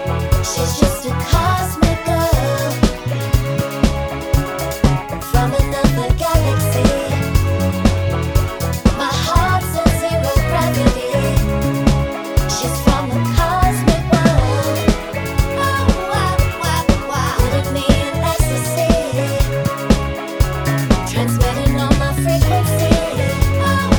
no Backing Vocals Dance 4:03 Buy £1.50